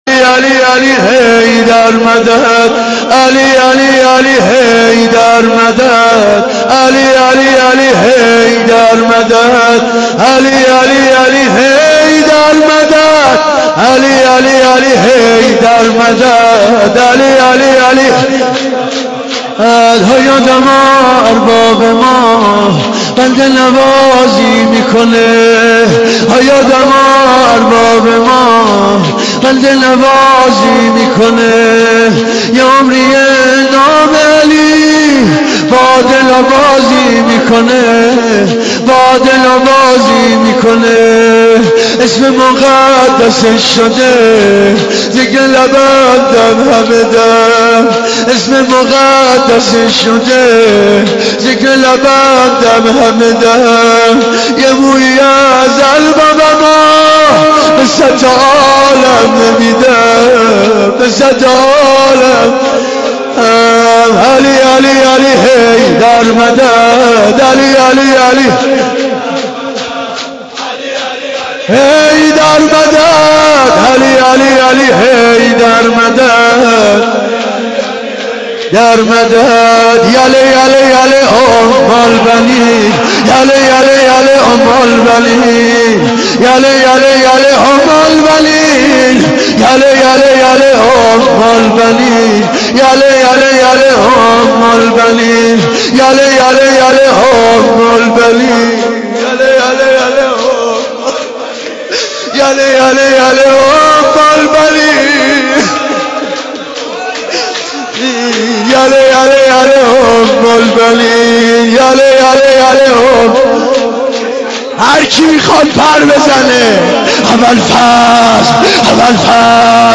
شور سینه زنی